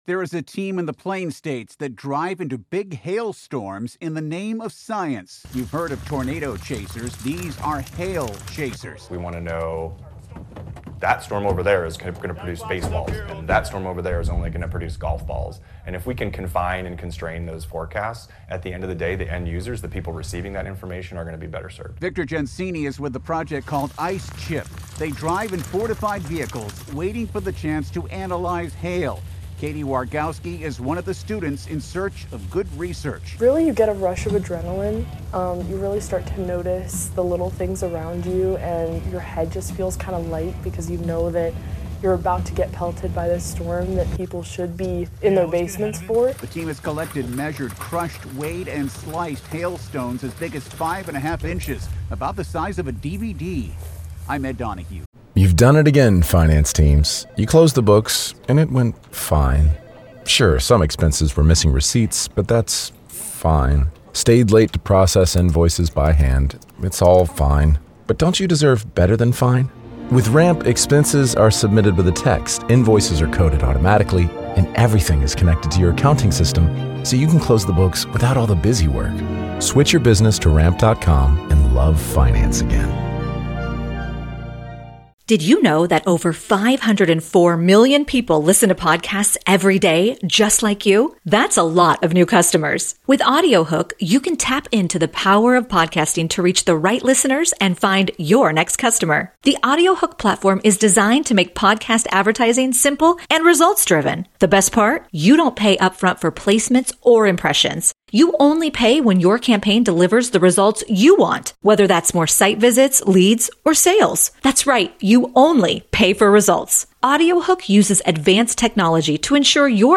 Ping, ping ping. Here's what it's like to drive into a big hailstorm in the name of science